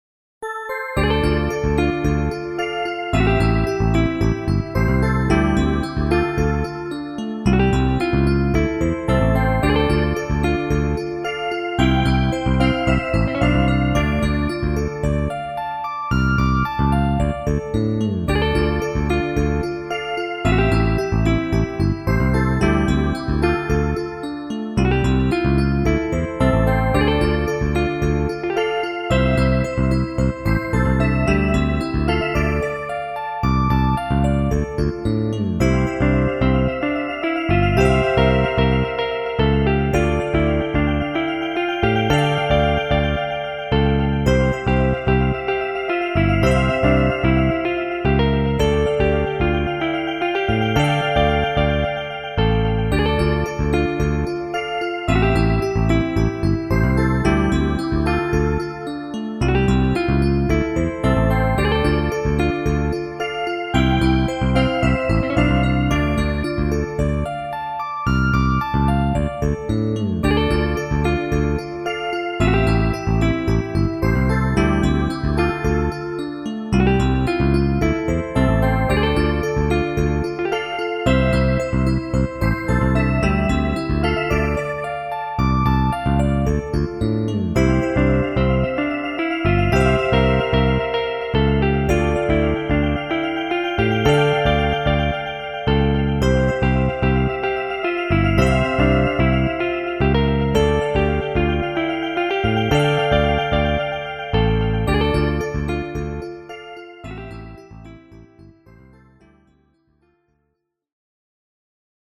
０１６と対になる悲しい曲です。
後半パートでは立ち直りに期待を込めて「希望」を表現した（つもりの）旋律を付けてみました。
※音源はＳＣ８８Ｐｒｏ。